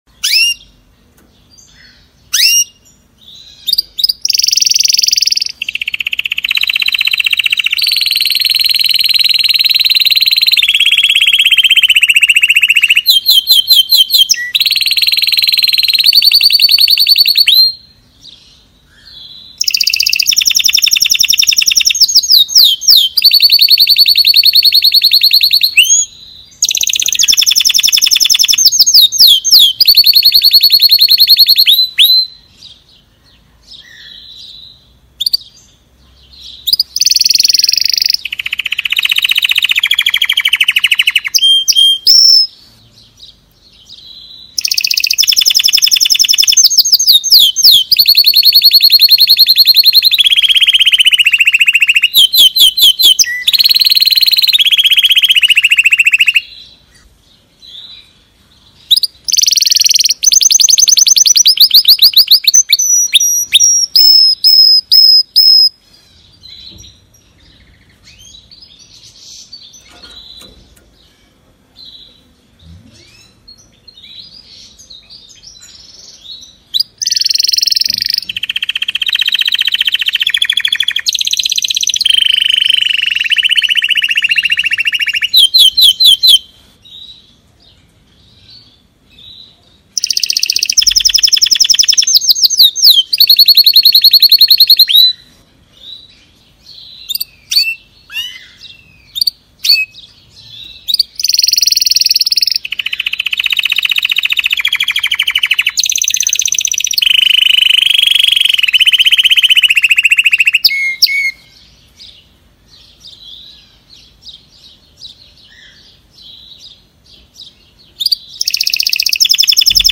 CANTO DE CANARIOS